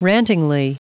Prononciation du mot rantingly en anglais (fichier audio)
Prononciation du mot : rantingly